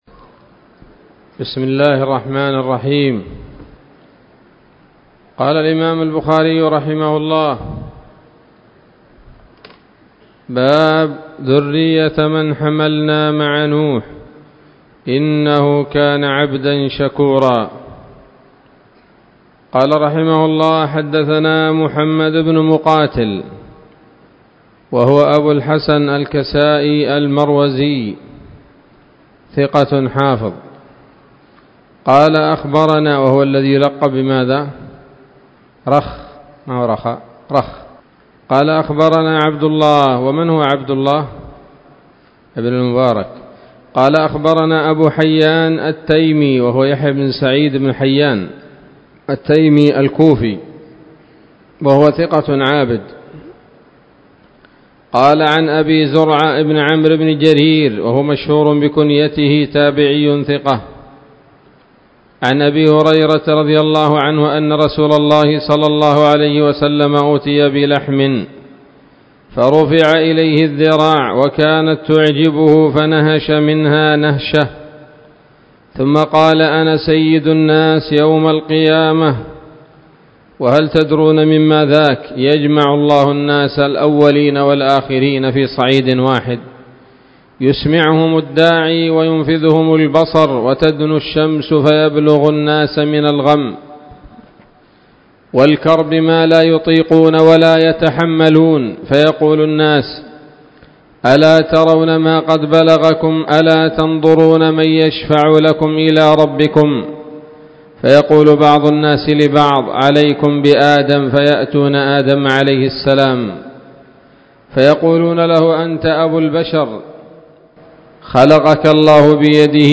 الدرس الخامس والخمسون بعد المائة من كتاب التفسير من صحيح الإمام البخاري